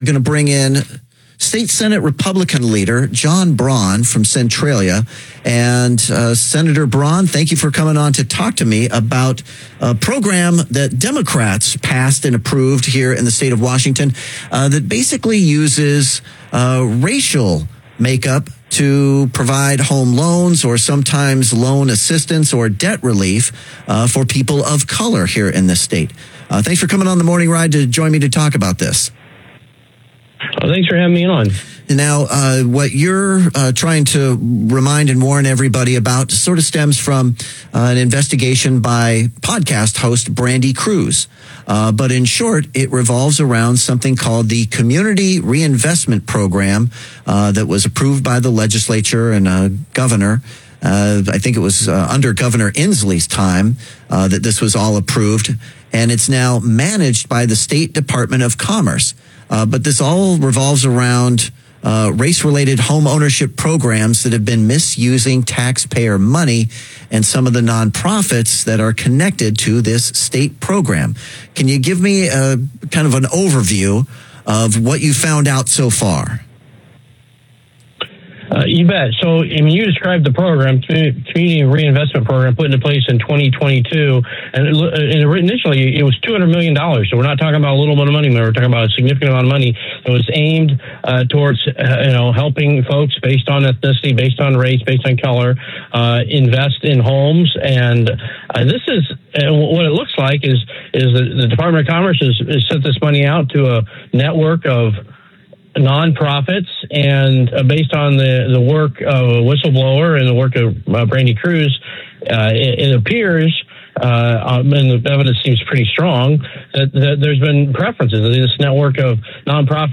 Sen. John Braun joins KVI’s Morning Ride to discuss an investigation into alleged misuse of taxpayer funds tied to a race-restricted homeownership program.